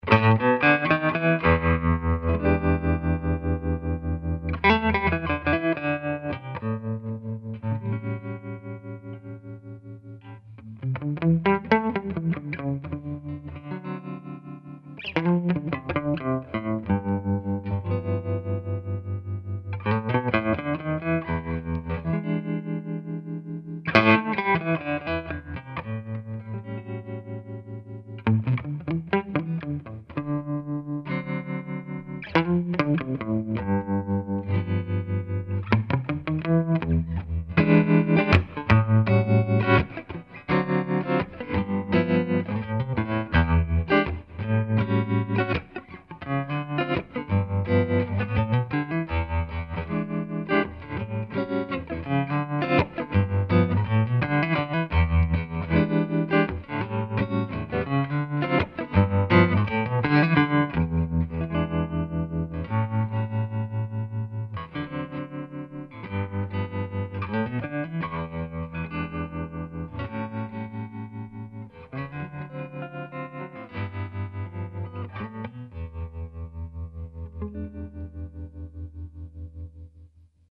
REC: Rode NT1 and a Sure sm57 into Cubase | No effects added.